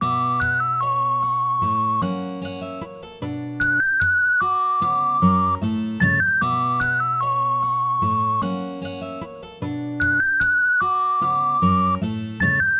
This sample pack contains 10 loops